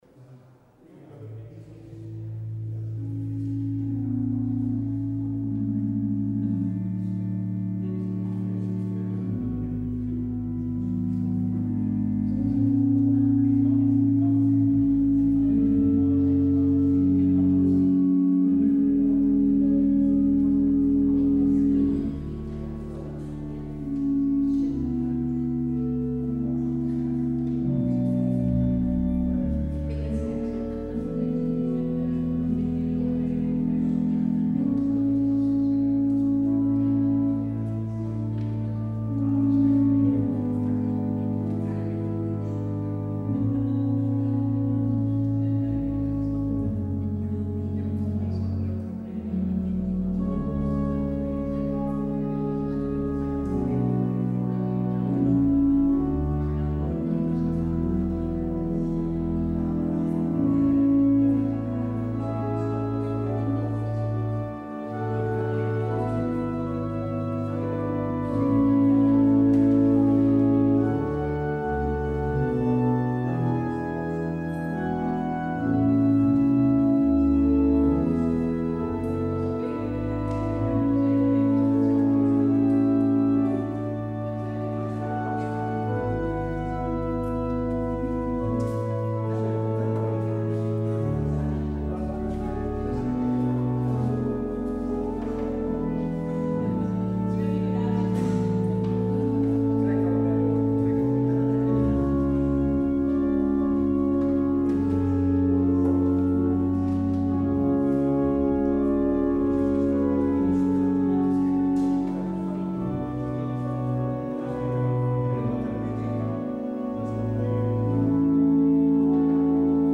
 Luister deze kerkdienst hier terug: Alle-Dag-Kerk 3 september 2024 Alle-Dag-Kerk https